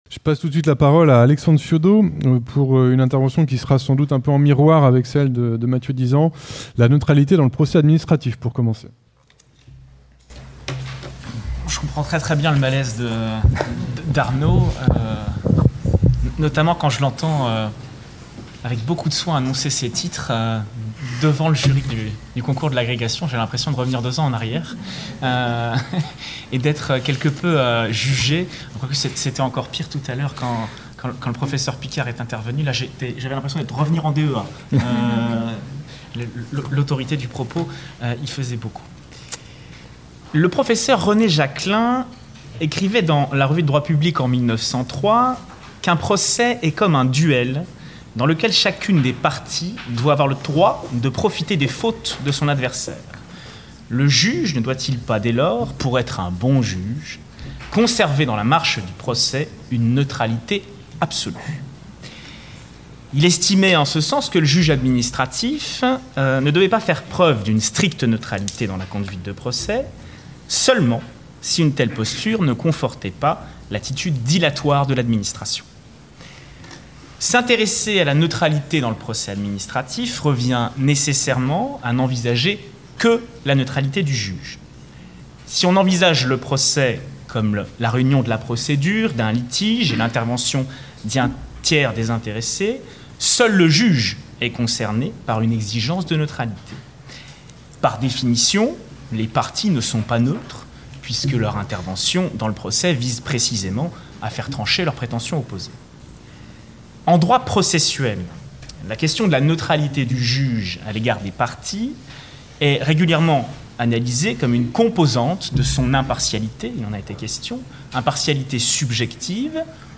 Retour sur le colloque : La neutralité (13 et 14 octobre 2016) Colloque de la promotion 2014 de l'agrégation de droit public Le colloque des 13 et 14 octobre 2016, réunissant les lauréats et membres du jury du concours d’agrégation de droit public 2014, a été l’occasion de mettre en perspective avec succès la neutralité en droit, en interrogeant ses raisons d’être et ses formes, mais en insistant aussi sur ses limites voire ses impasses.